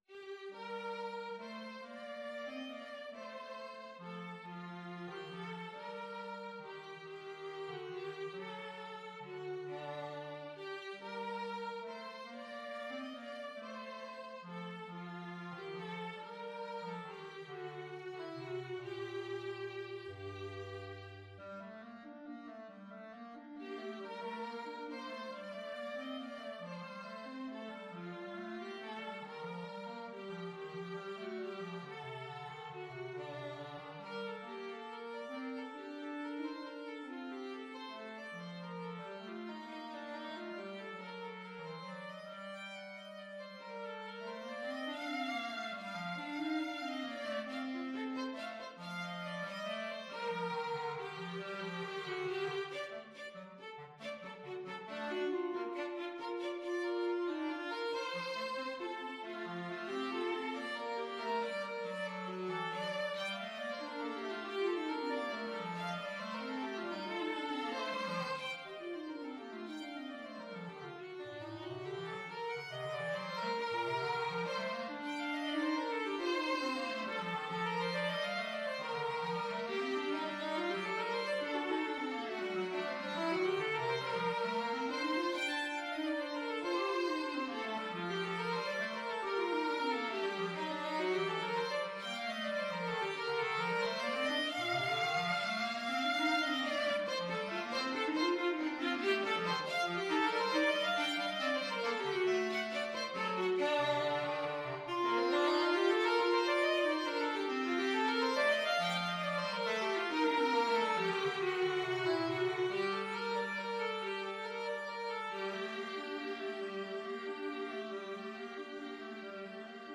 ViolinClarinet/Bass Clarinet
3/4 (View more 3/4 Music)
Slow one in a bar feel . = c.46
Classical (View more Classical Clarinet-Violin Duet Music)